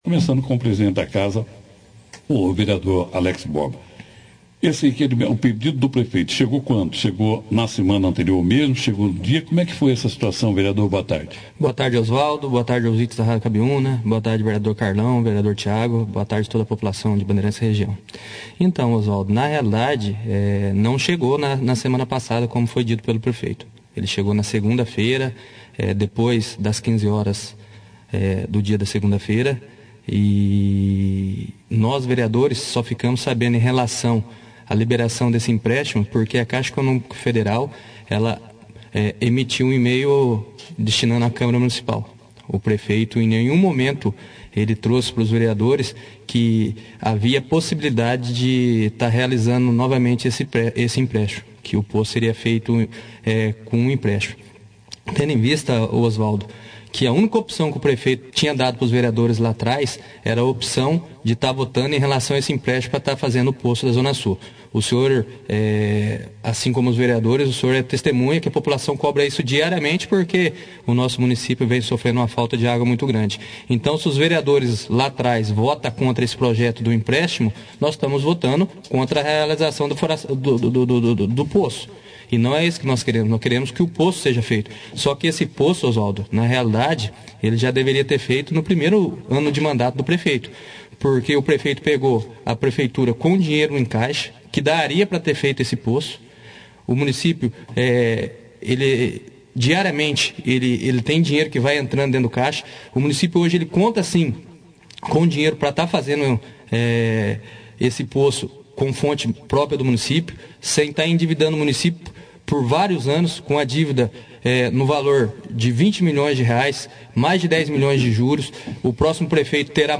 Neste sábado, 08/07, o Presidente da Câmara de Bandeirantes, Alex Borba, juntamente com os Vereadores Carlão Demicio e Tiago Pobreza,(foto),participaram de uma entrevista no jornal Operação Cidade, abordando a recente controvérsia em torno de um pedido feito à Câmara Municipal.